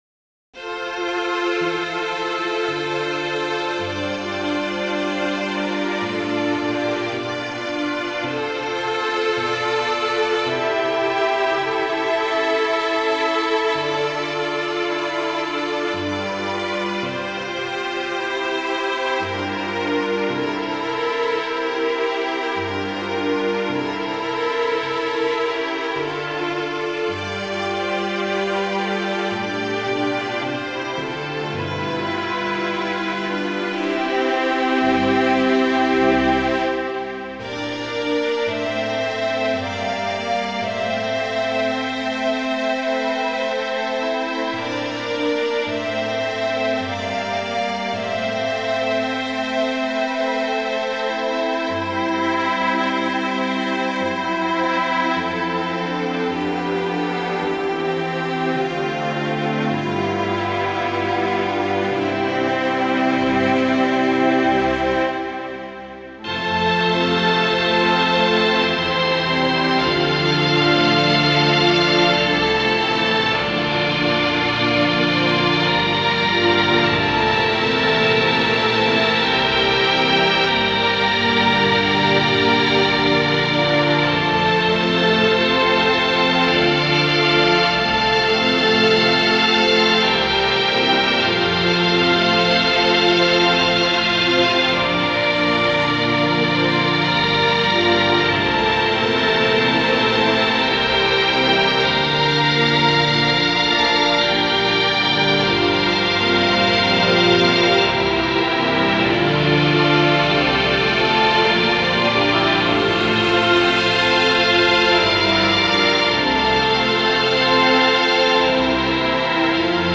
Another teary Italian tune familiar to many (especially fans of boxing films) whose origins are more interesting than most.